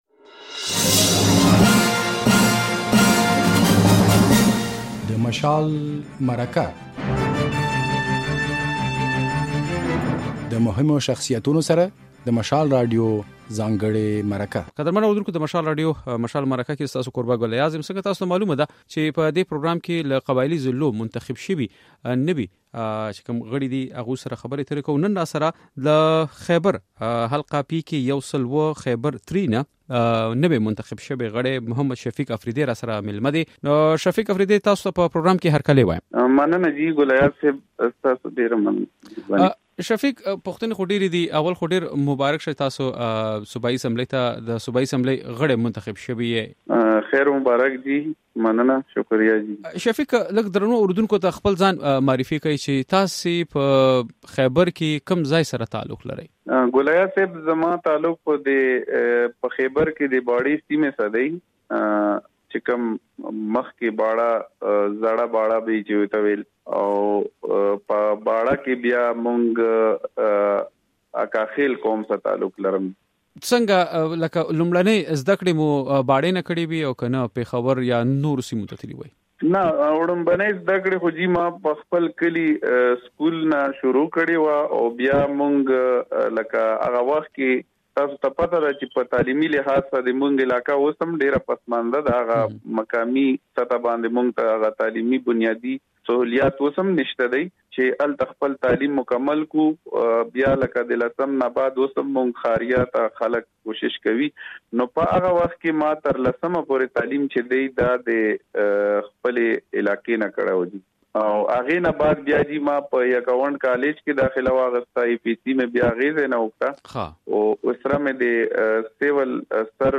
مشال مرکه